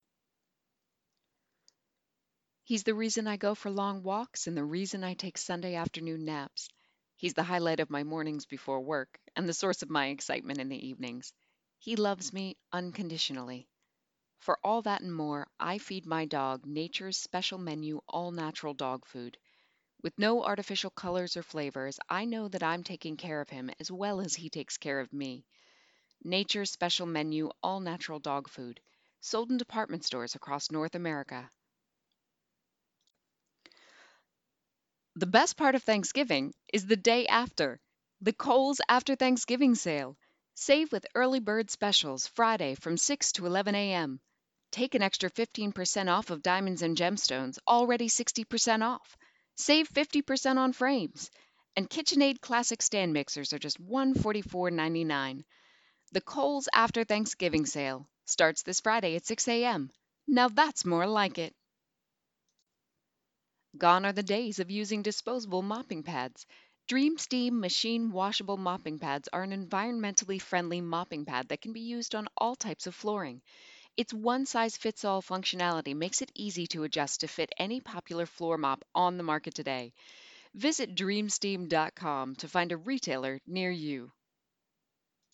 VO Reel